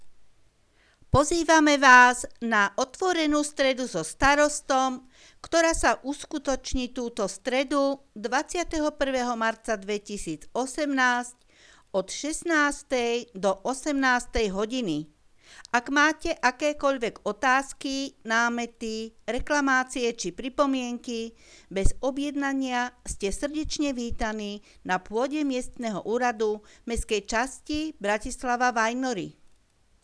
Hlásenia miestneho rozhlasu 20.3.2018 (Otvorená streda so starostom - pozvánka)